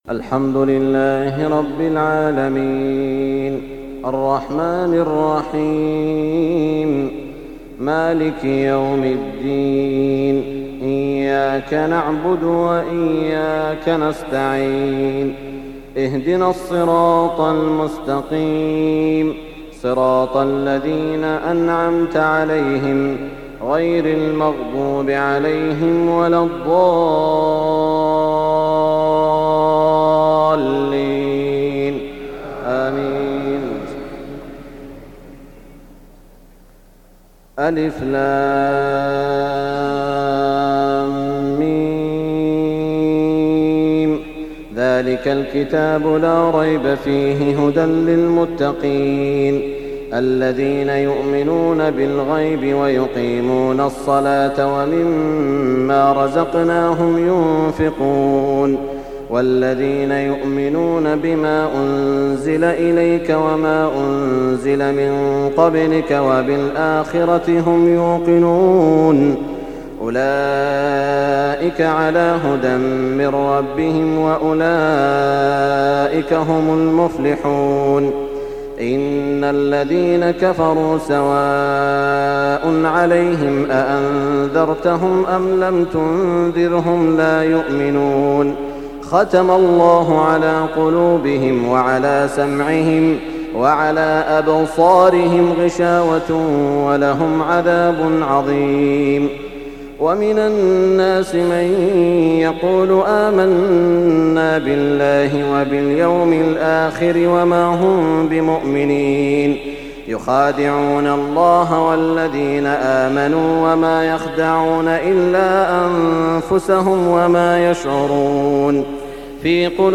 تهجد ليلة 21 رمضان 1420هـ من سورة البقرة (1-91) Tahajjud 21 st night Ramadan 1420H from Surah Al-Baqara > تراويح الحرم المكي عام 1420 🕋 > التراويح - تلاوات الحرمين